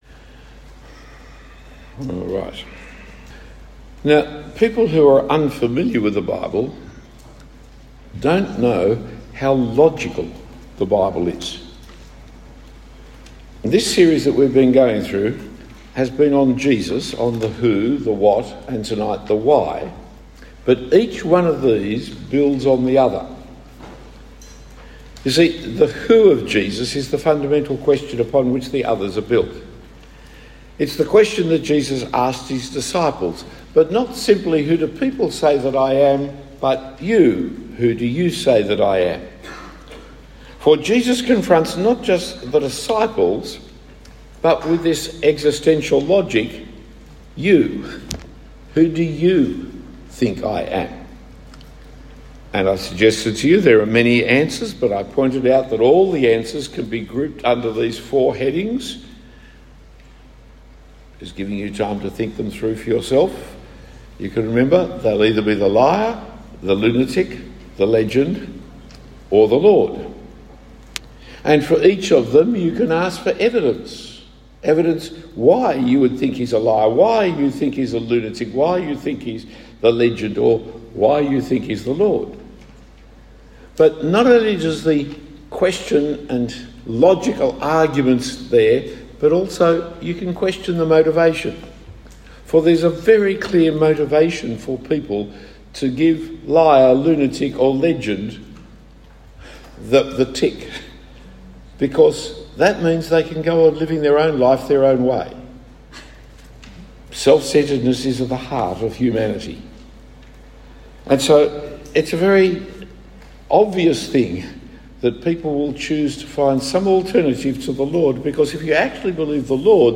Talk 3 of 6 given at Launch 2025, a camp for school leavers keen to live for Jesus as they commence university life.